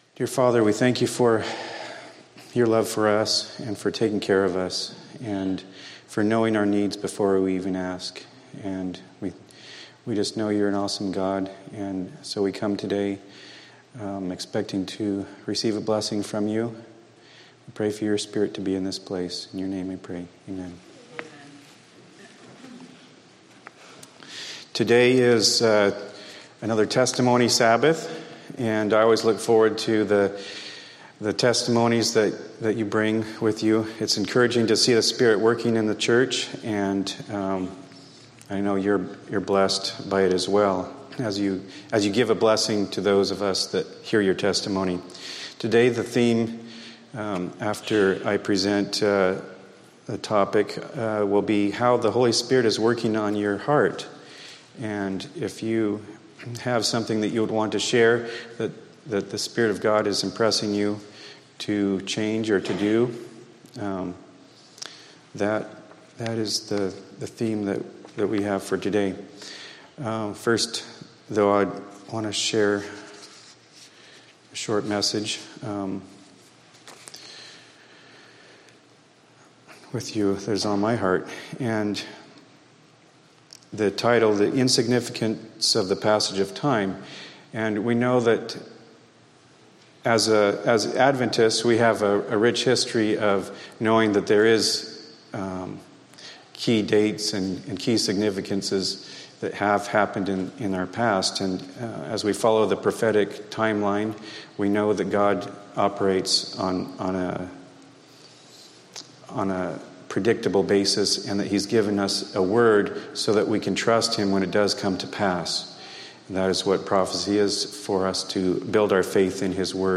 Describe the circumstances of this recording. Sabbath Fellowship Group